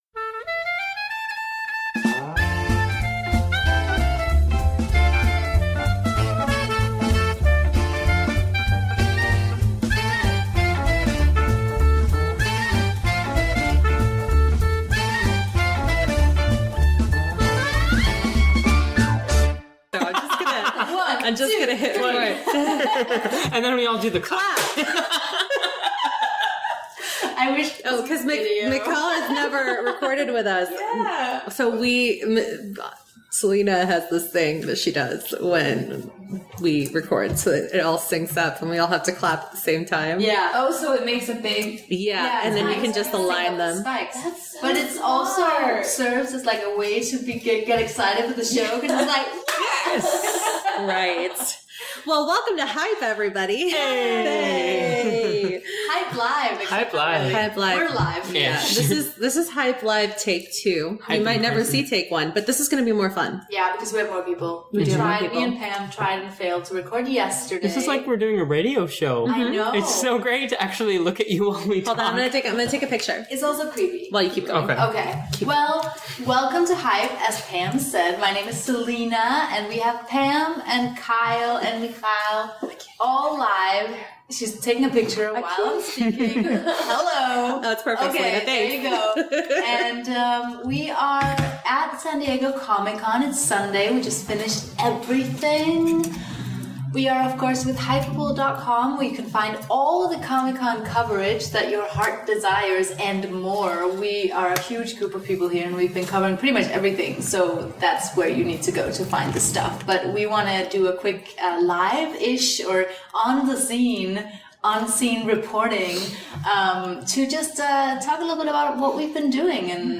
Hype Special #15 – Live-ish From San Diego Comic-Con 2016